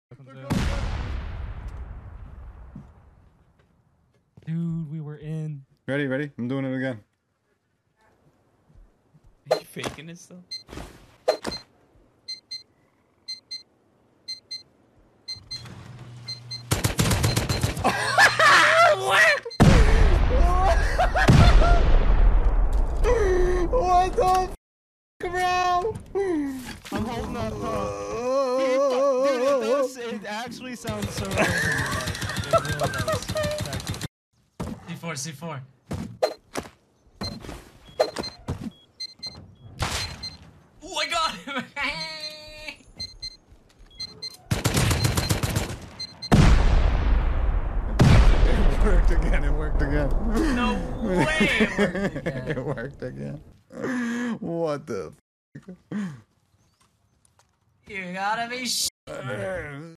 C4 Sound BAIT to go sound effects free download